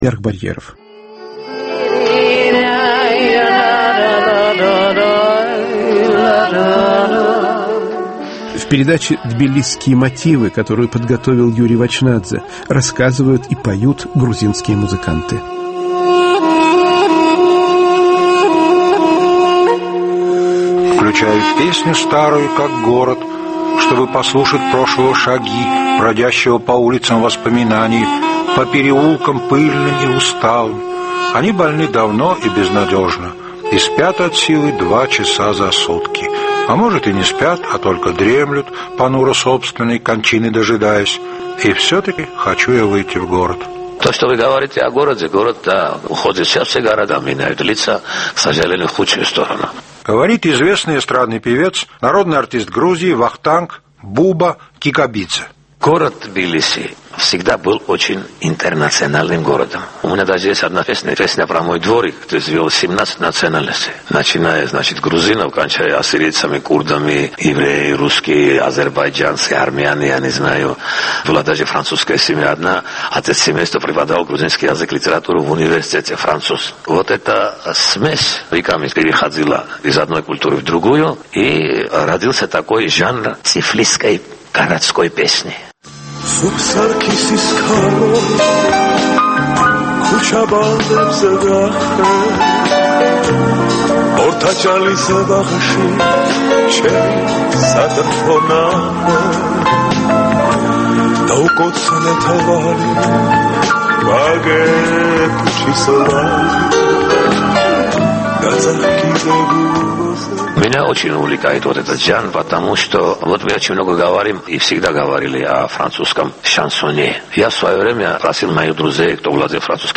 "Тбилисские мотивы": городской грузинский романс.